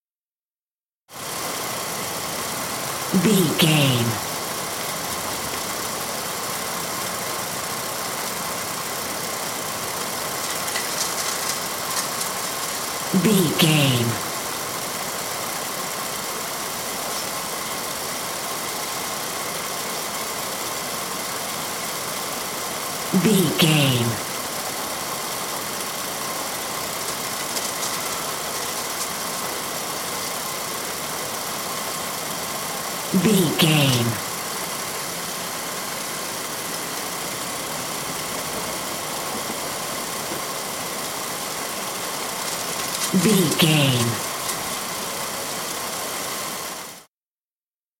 Airport baggage carousel cargo
Sound Effects
urban
airport sounds